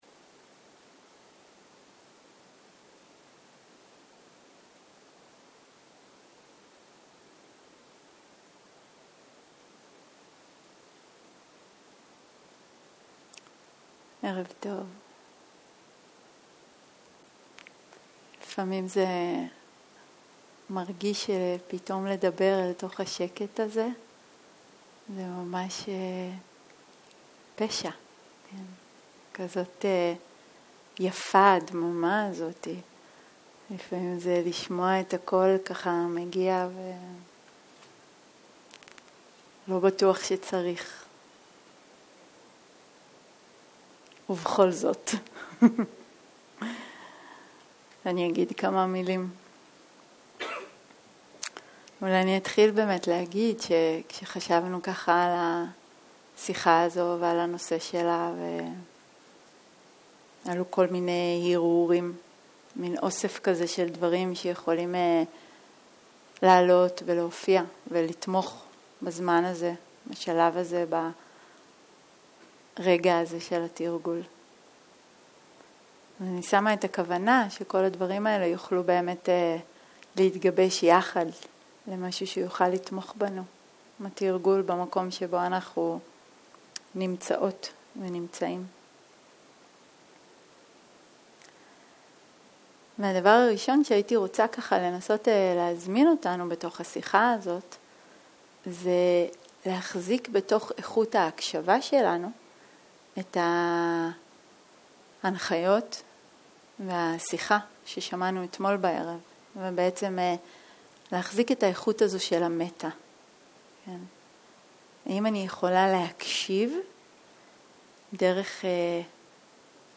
שיחות דהרמה שפת ההקלטה